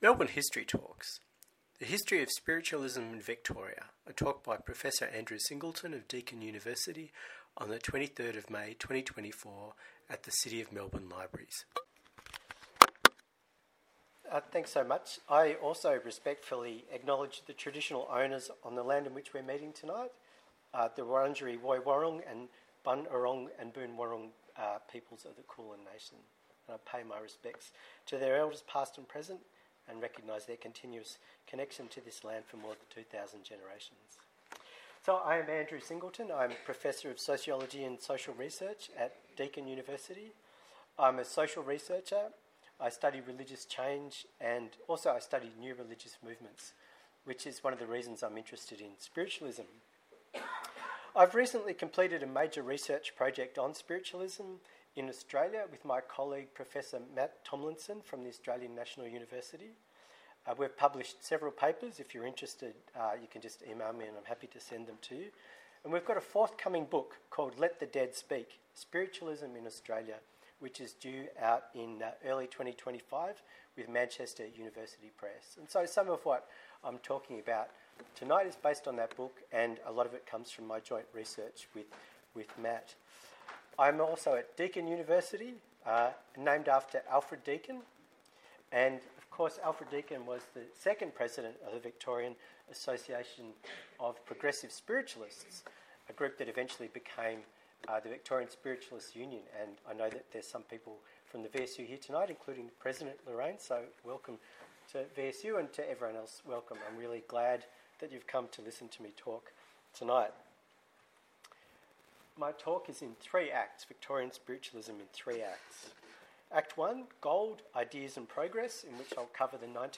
This is a recording of the event, allowing listeners to revisit the stories and insights shared on the night.